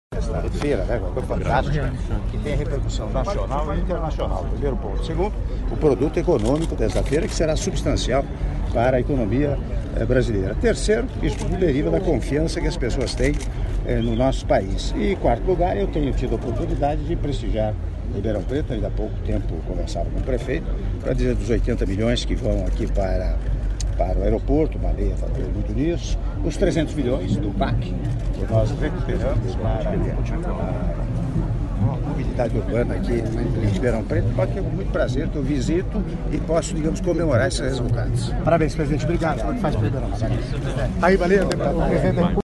Entrevista exclusiva concedida pelo Presidente da República, Michel Temer, à Rádio Bandeirantes/SP - (00:42s)